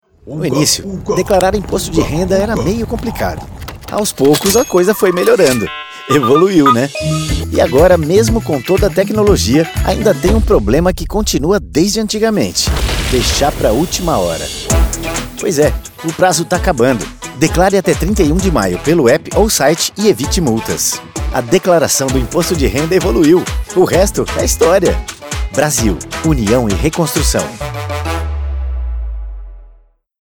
5-_spot_de_radio_30_irpf_prazo.mp3